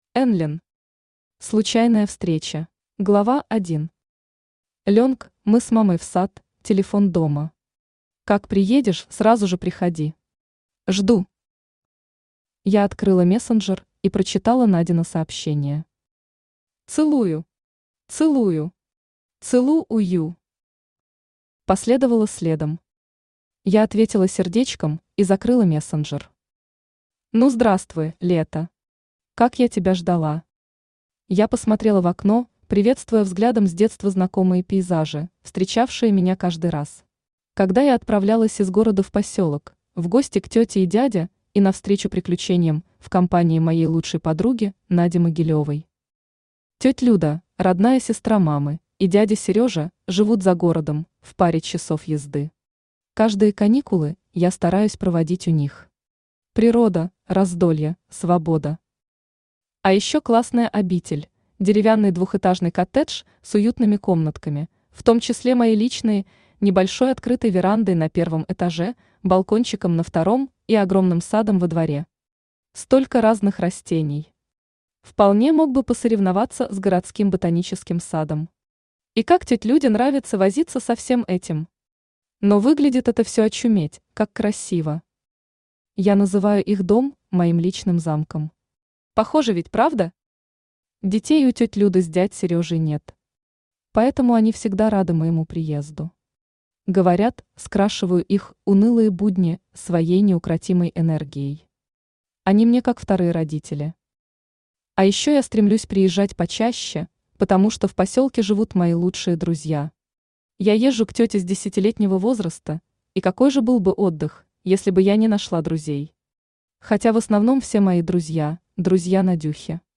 Аудиокнига Случайная встреча | Библиотека аудиокниг
Aудиокнига Случайная встреча Автор Энлин Читает аудиокнигу Авточтец ЛитРес.